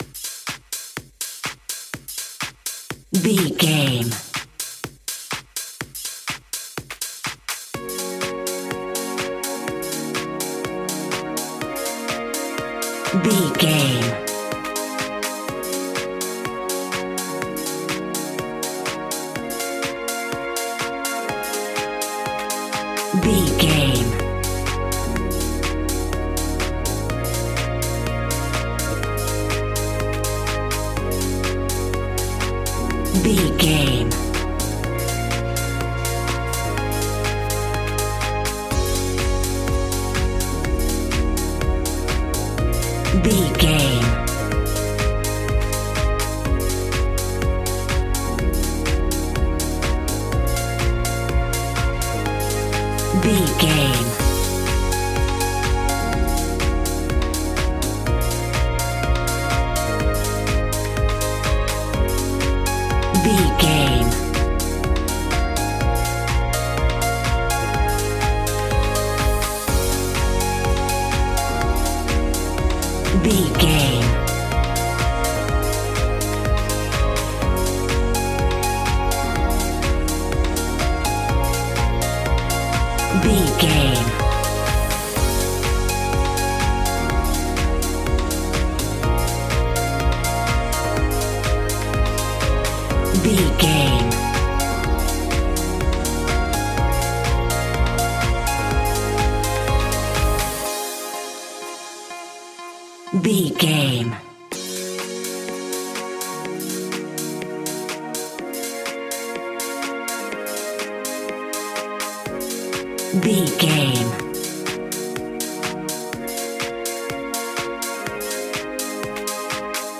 Aeolian/Minor
B♭
groovy
futuristic
hypnotic
uplifting
drum machine
synthesiser
house
electro
funky house
synth bass